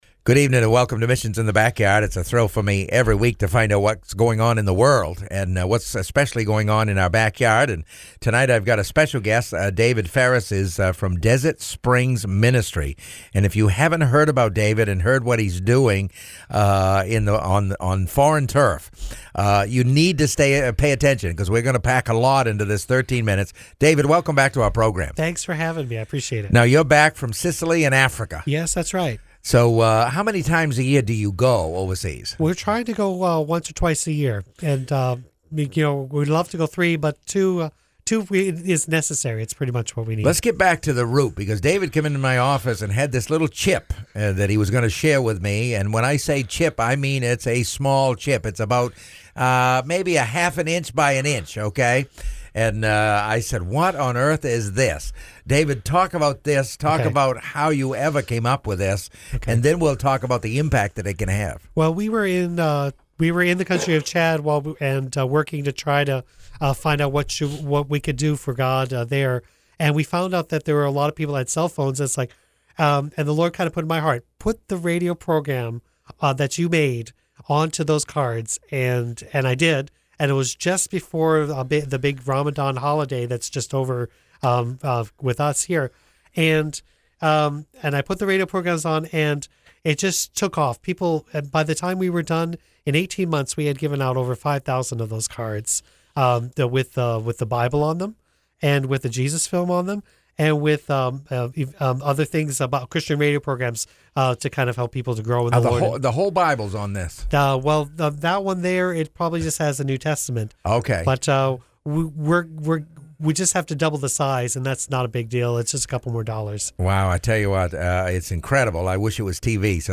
About the Radio Interview